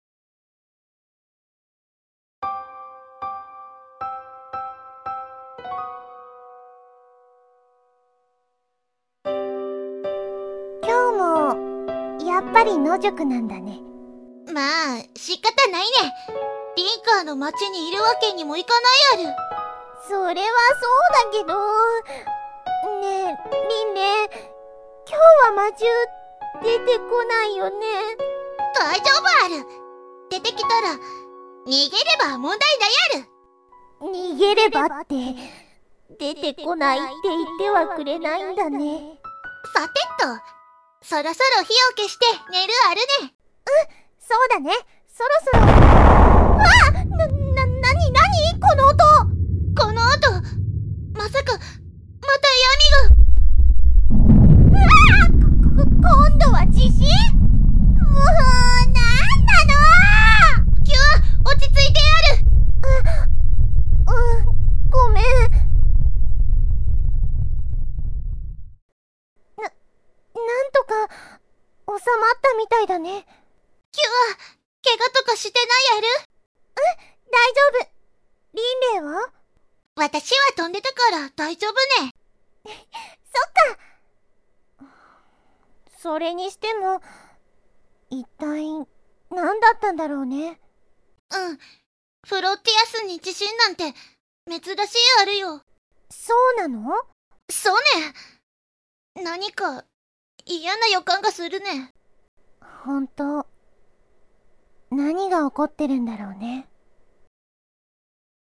Flotias-2つの世界- *ドラマ「キュア編」*